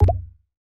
DrumBamboo Pop Notification 2.wav